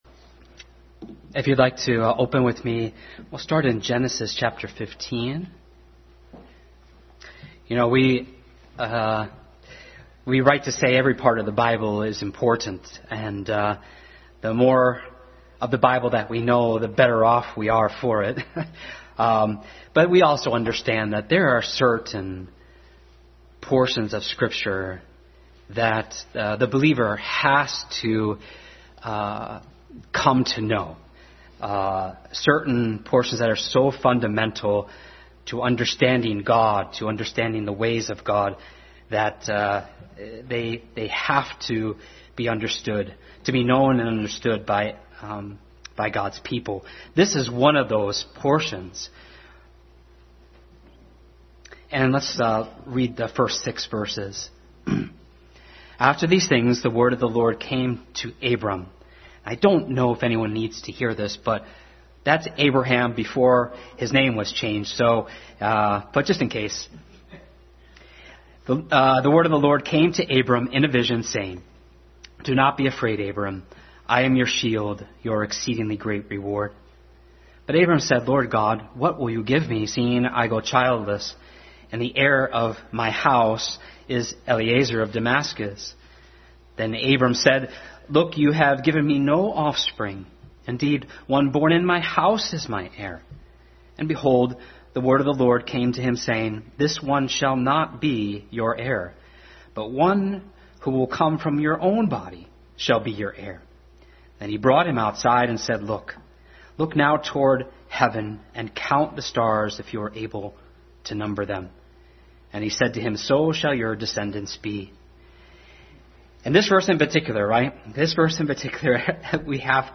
Passage: Genesis15:1-6, 17, Romans 3:19-22, 4:1-22, Philippians 3:10 Service Type: Sunday School